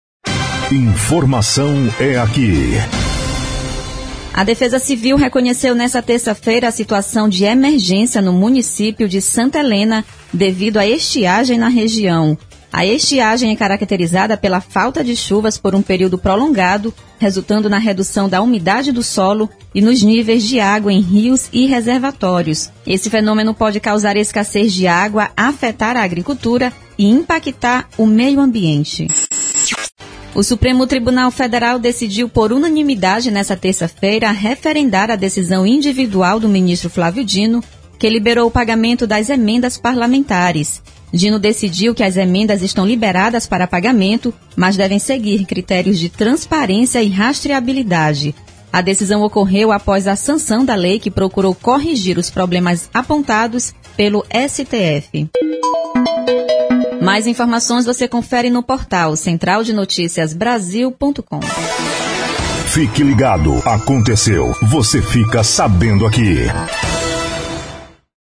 Todos os Plantões de Notícias
Repórter